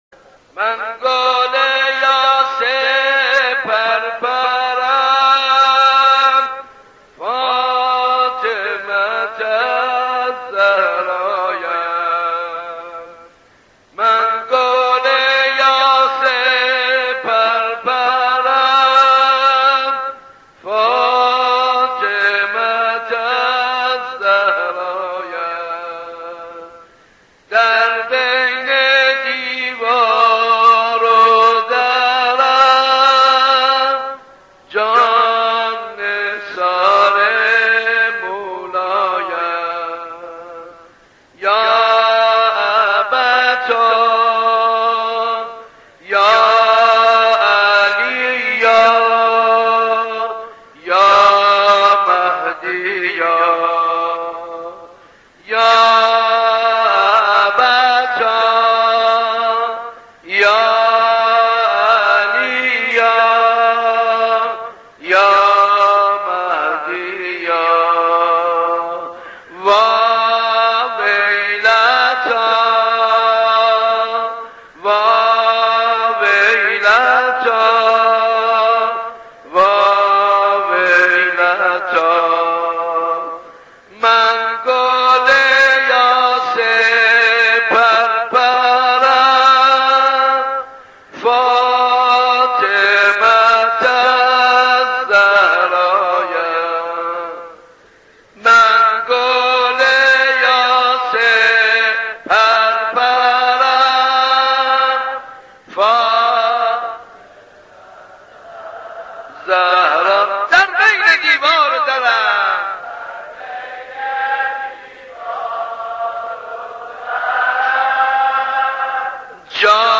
نوحه‌سرایی در اندوه شهادت حضرت زهرا(س